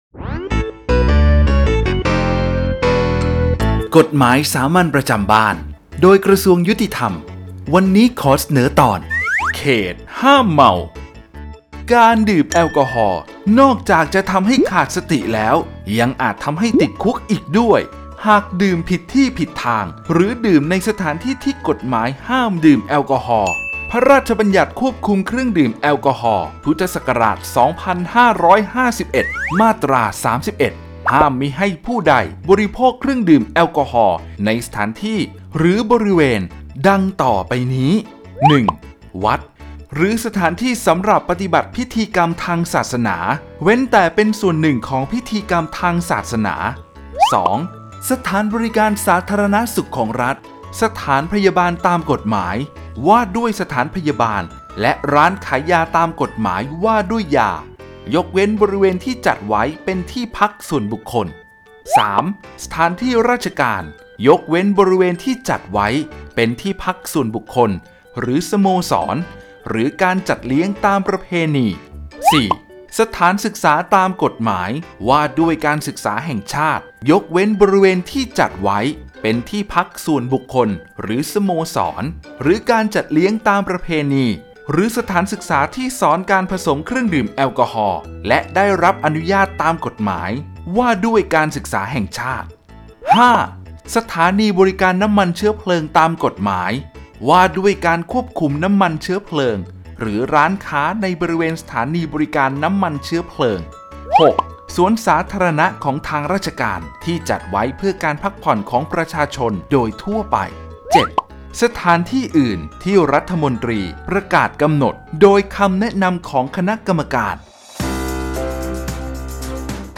กฎหมายสามัญประจำบ้าน ฉบับภาษาท้องถิ่น ภาคกลาง ตอนเขตห้ามเมา
ลักษณะของสื่อ :   คลิปเสียง, บรรยาย